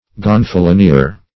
Search Result for " gonfalonier" : The Collaborative International Dictionary of English v.0.48: Gonfalonier \Gon`fa*lon*ier"\, n. [F. gonfalonier: cf. It. gonfaloniere.]